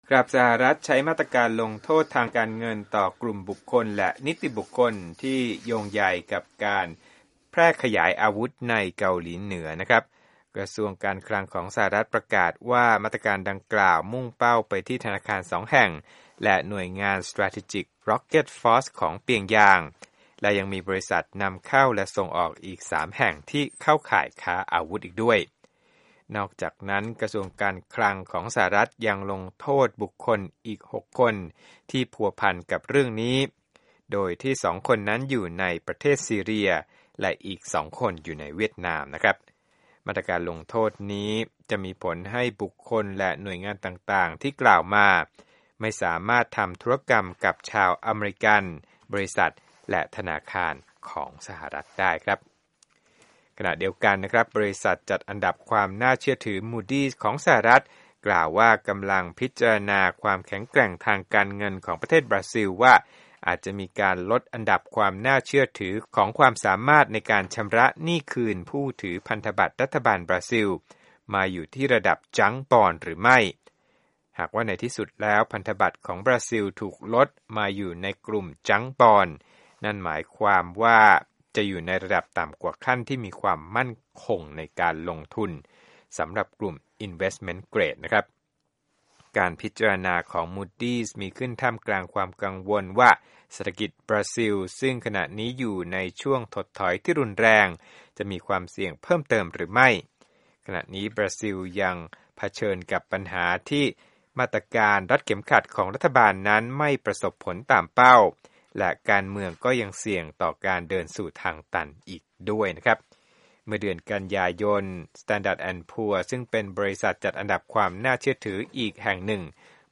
รวมข่าวธุรกิจ 12/9/2015